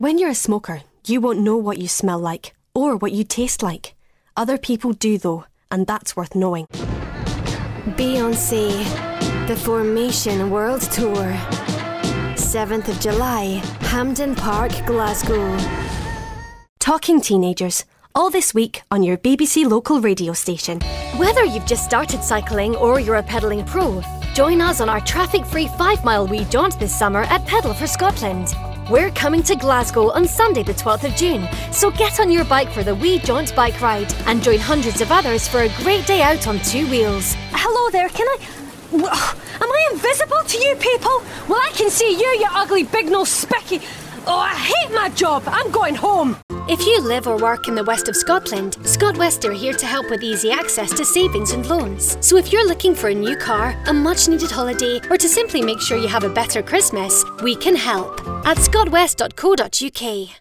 Scottish Accent Showreel
Female
Central Scottish
Confident
Friendly